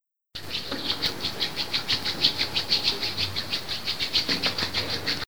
Frote de tejido
Grabación sonora que capta el sonido de la acción del frotar entre tejidos (fricción de una parte del tejido frotándose contra otra).
fricción
Sonidos: Acciones humanas